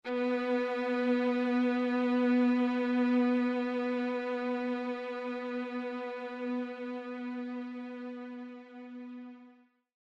Нота: Си первой октавы (B4) – 493.88 Гц
Note8_B4.mp3